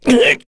Kain-Vox_Damage_kr_05.wav